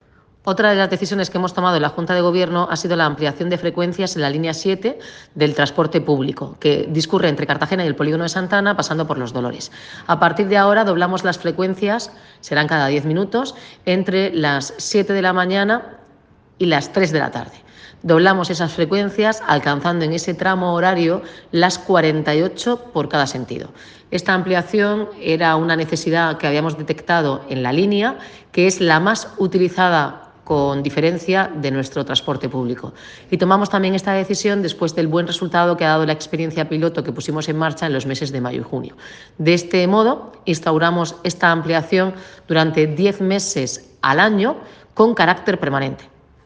Volver al listado Multimedia Declaraciones de la alcaldesa, Noelia Arroyo.